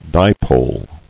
[di·pole]